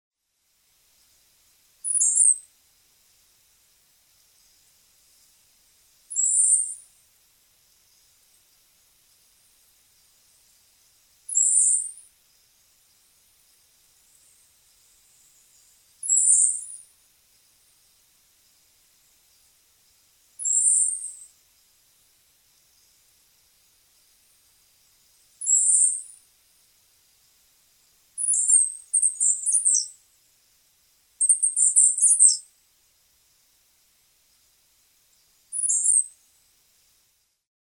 Haplospiza unicolor - Cigarra
Haplospiza unicolor.mp3